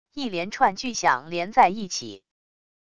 一连串巨响连在一起wav下载